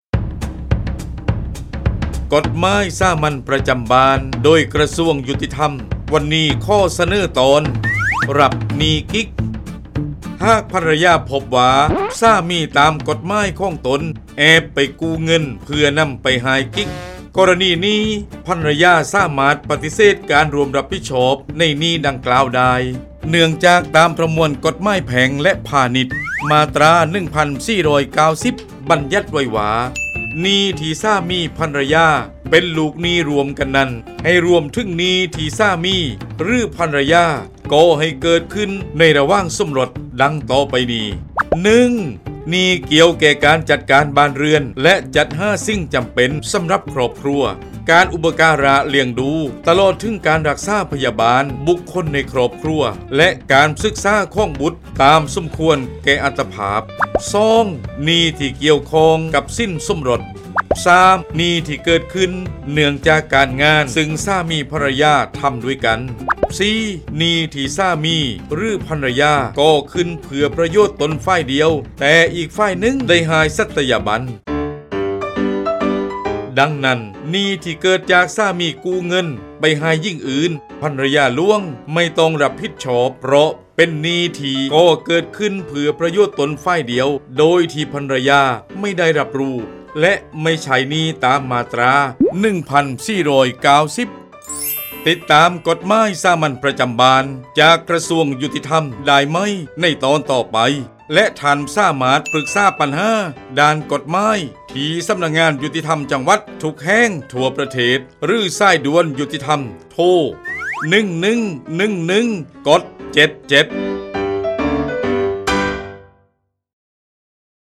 ชื่อเรื่อง : กฎหมายสามัญประจำบ้าน ฉบับภาษาท้องถิ่น ภาคใต้ ตอนรับหนี้กิ๊ก
ลักษณะของสื่อ :   คลิปเสียง, บรรยาย